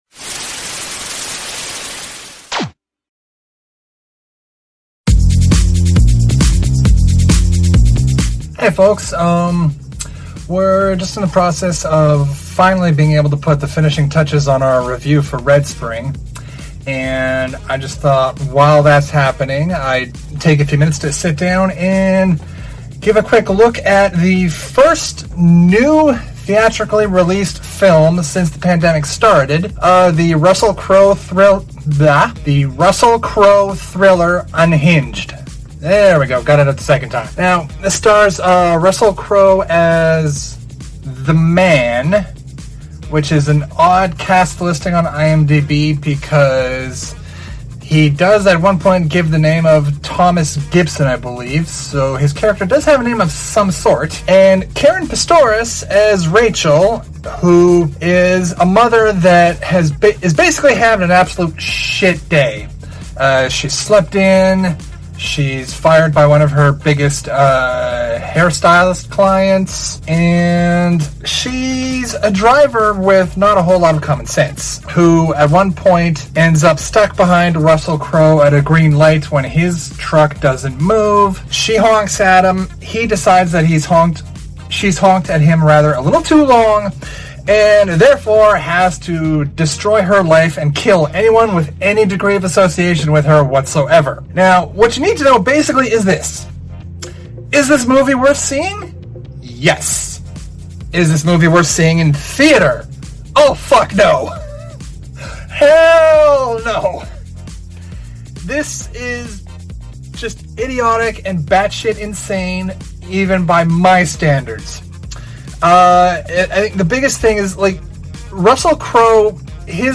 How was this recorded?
Recorded in Halifax, NS, Canada